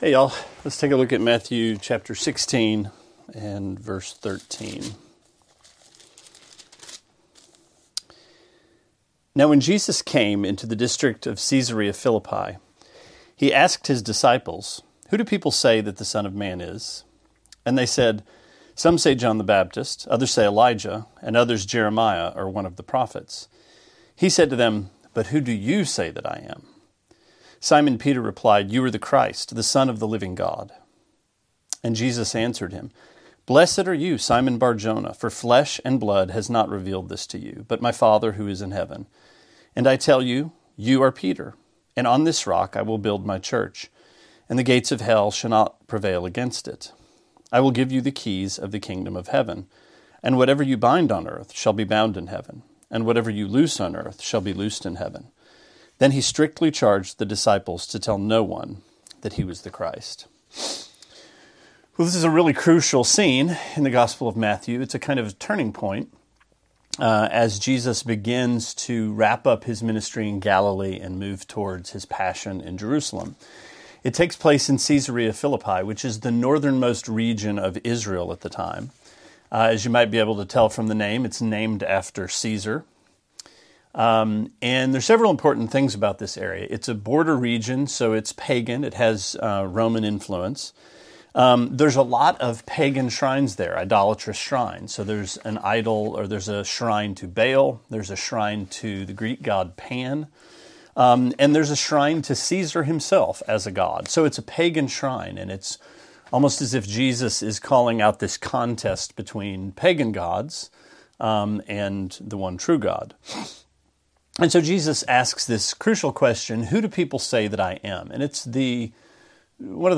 Sermonette 3/6: Matthew 16:13-20: How Will You Answer?